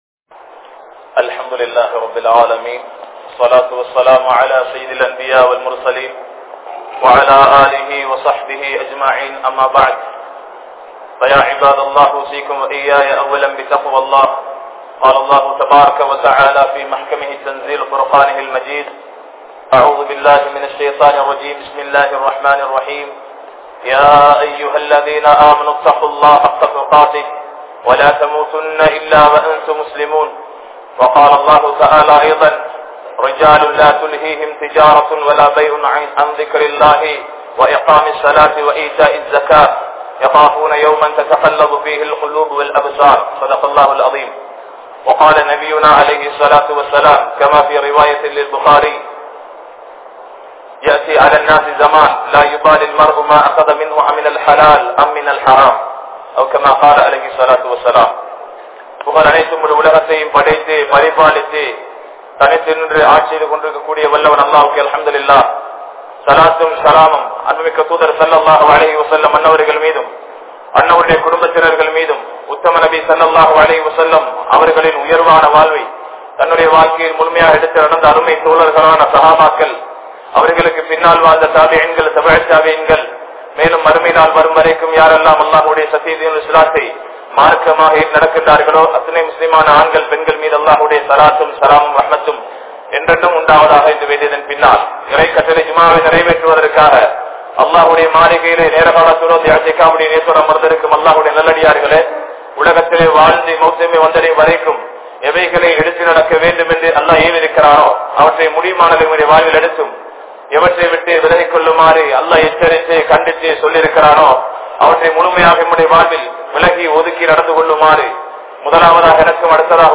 Ungalai Narahaththitku Kondu Sellum Haraam (உங்களை நரகத்திற்கு கொண்டு செல்லும் ஹராம்) | Audio Bayans | All Ceylon Muslim Youth Community | Addalaichenai